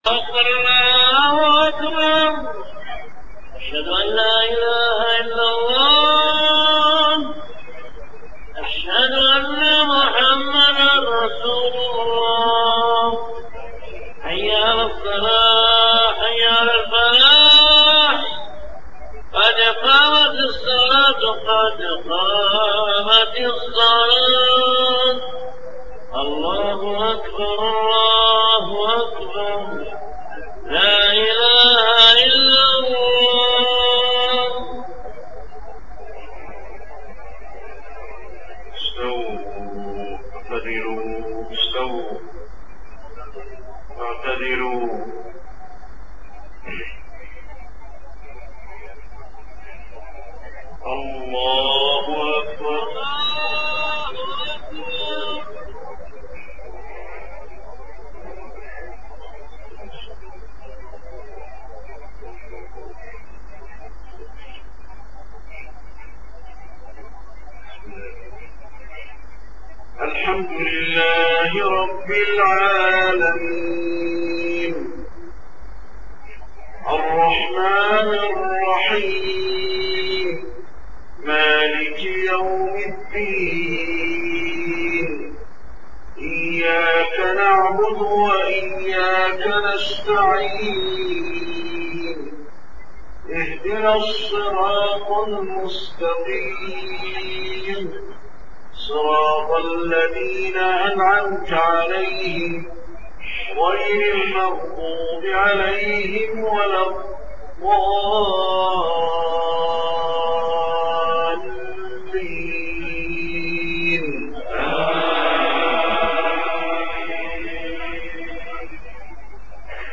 صلاة العشاء 1 محرم 1430هـ خواتيم سورة الفرقان 61-77 > 1430 🕌 > الفروض - تلاوات الحرمين